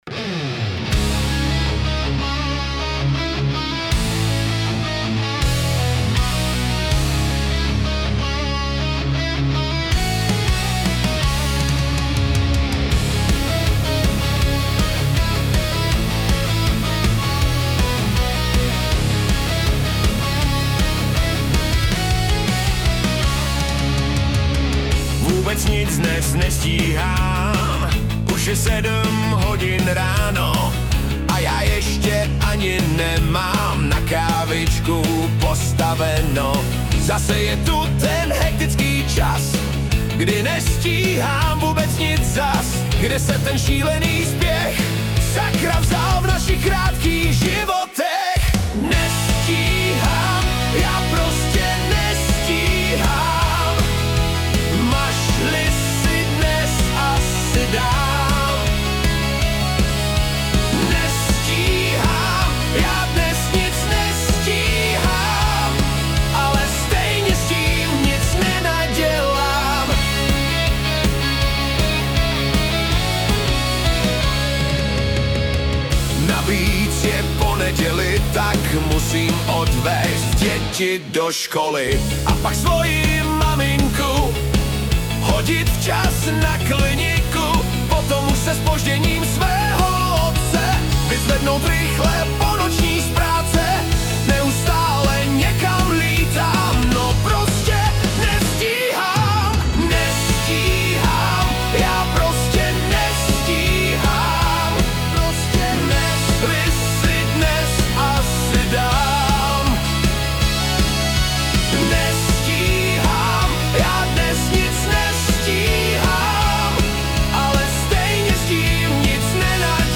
Prvotně použito Suno 3, v roce 2025 remasterováno pomocí Suno 4.5.